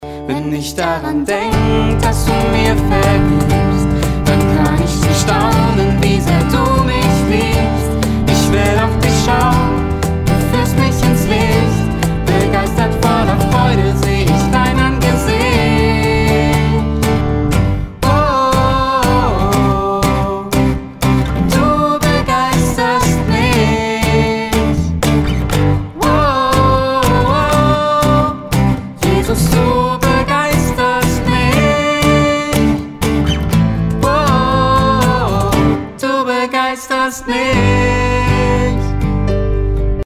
Notation: SATB
Tonart: G
Taktart: 4/4
Tempo: 112 bpm
Parts: 2 Verse, Refrain, Bridge
Worship, Liedvortrag